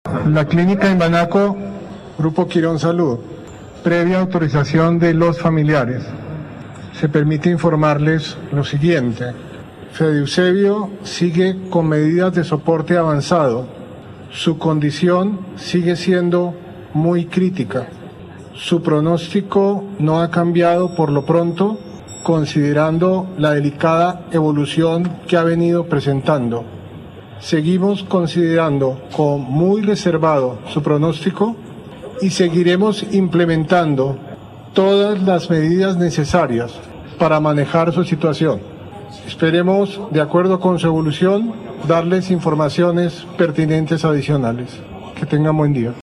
Parte Médico Clínica Imbanaco